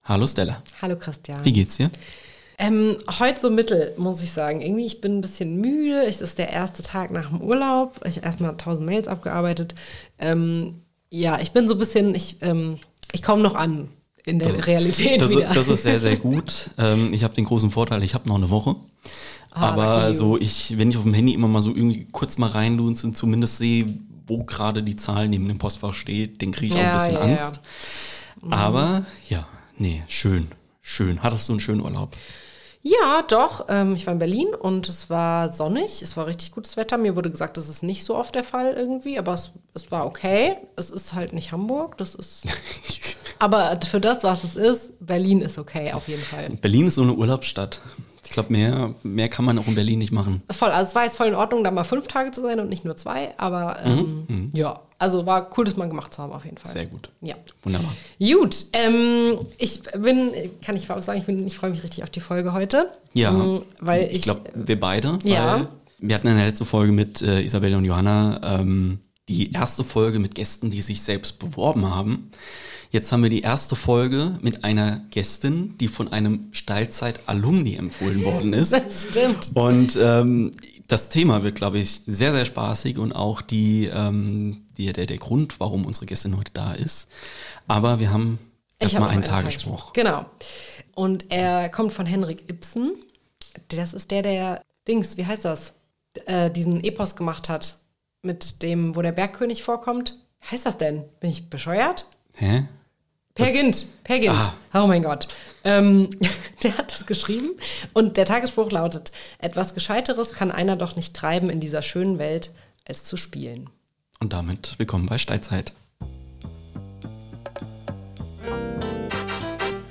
in unser Küchenstudio eingeladen